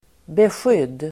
Uttal: [besj'yd:]